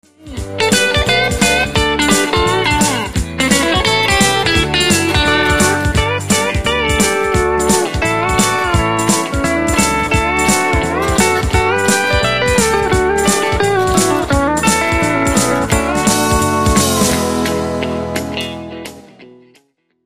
Hier im semiprof - Studio entstehen z.B. Demo-Produktionen, Rundfunkspots, Jingles, Halbplaybacks, Theatermusik... - einige Beispiel-Ausschnitte gibt's als MP3_files
Neben 'richtigen' Instrumenten gibt's diverse MIDI-Klangerzeuger, NEUMANN TLM 170 Micros, TLA Compressor, YAMAHA O2R-Mixer u.s.w.
Bsp Chorus Diät